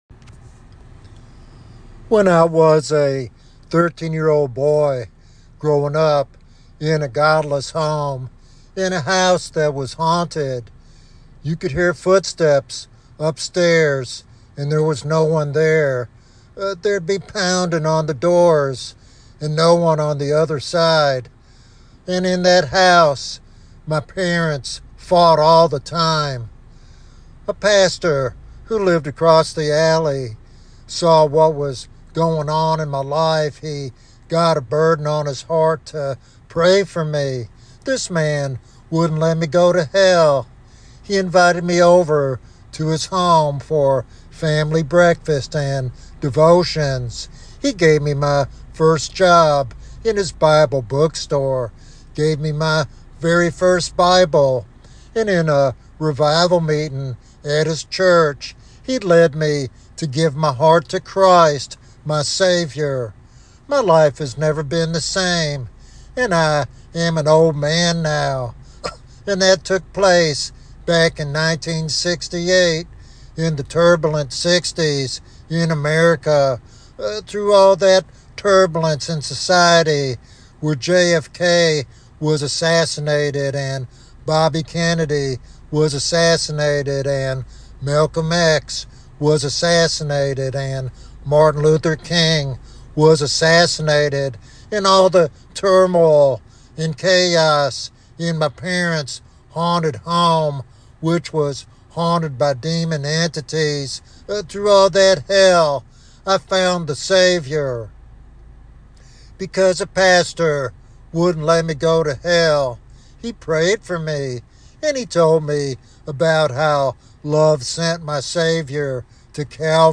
This sermon is a compelling evangelistic message about grace, repentance, and the power of Christ's sacrifice.